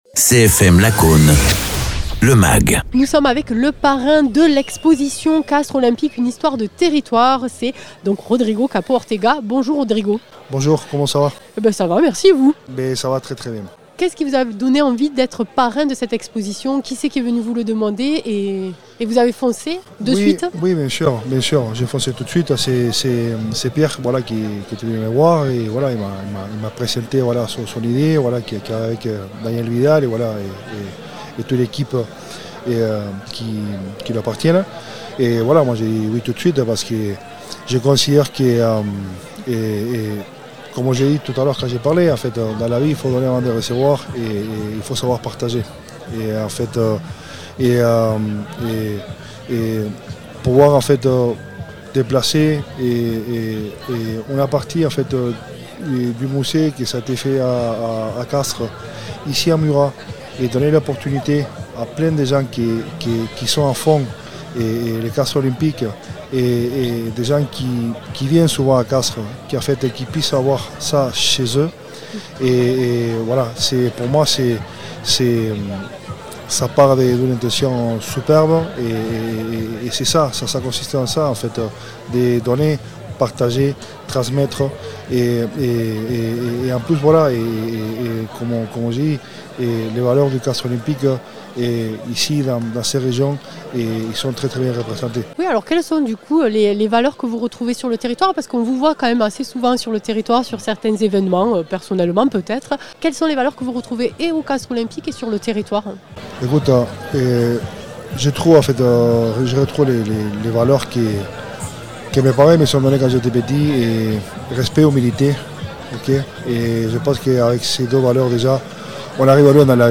Interviews
Invité(s) : Rodrigo Capo Ortega, ancien joueur du Castres Olympique et parrain de l’exposition